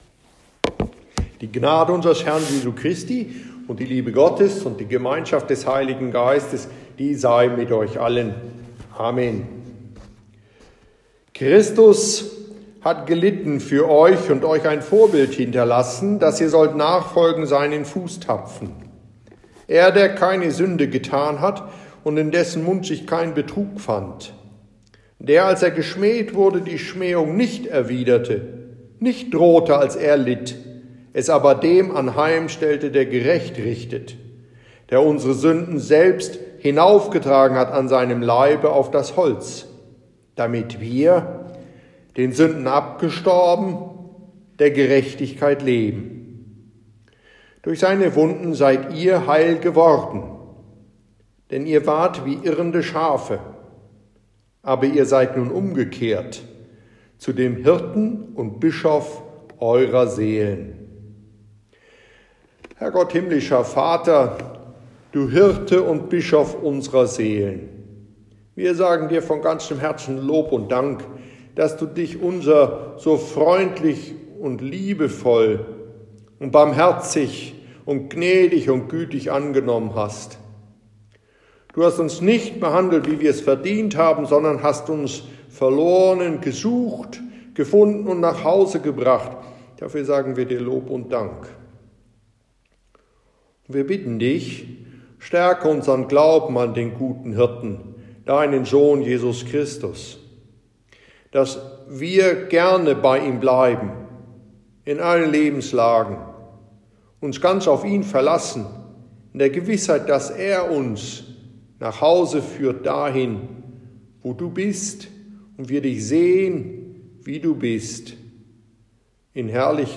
on the organ